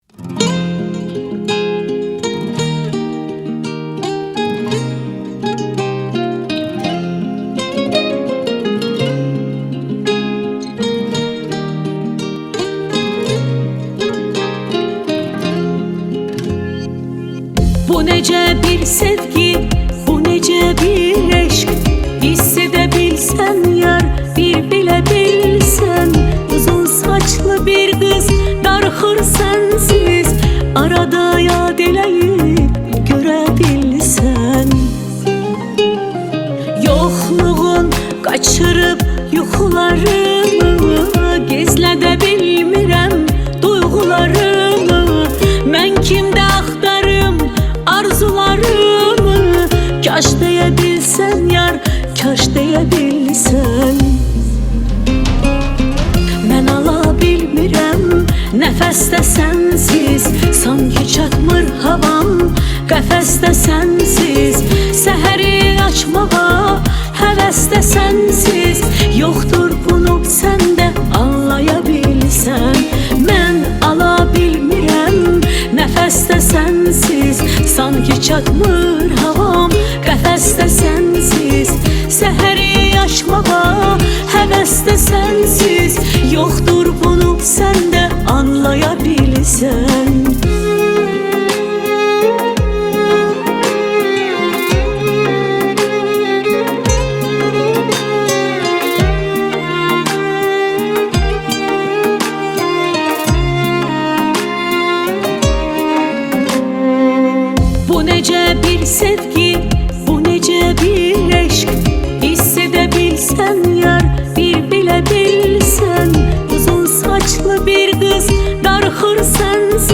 موزیک آذربایجانی
En Güzel Pop Müzikler Türkçe + Yeni şarkılar indir